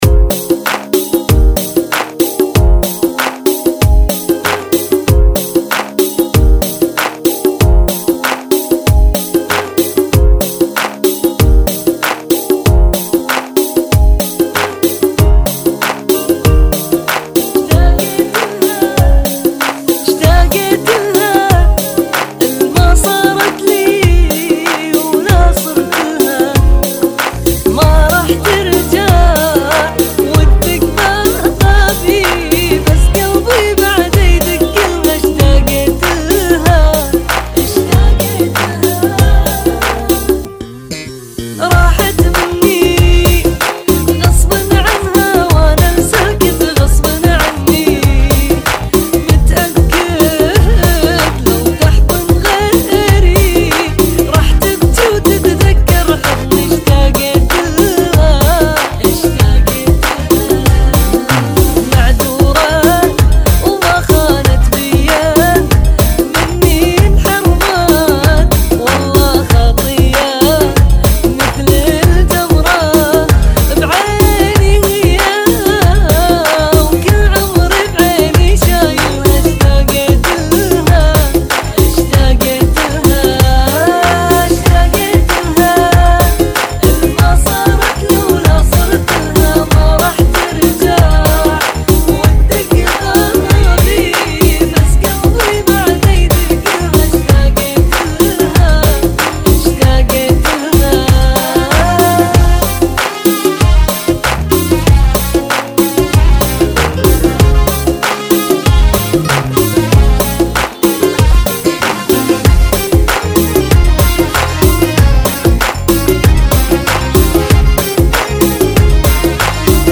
ريمكس